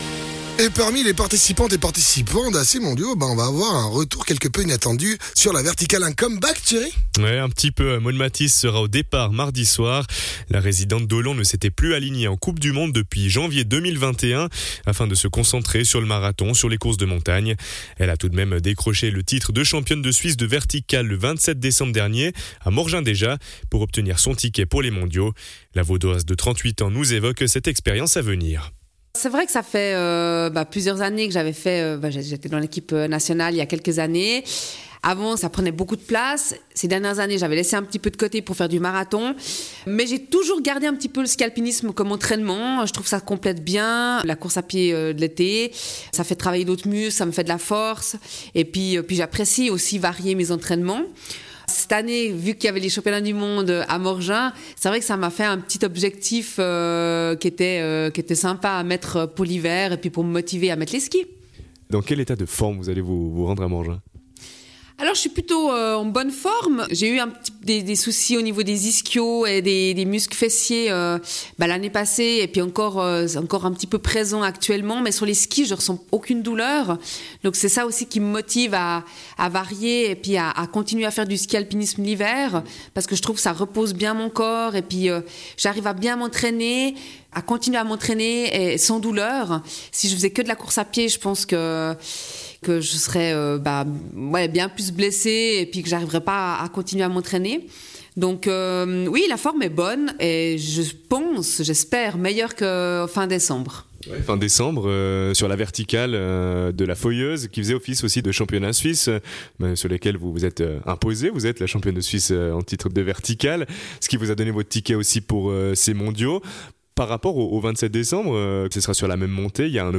Intervenant(e) : Maude Mathys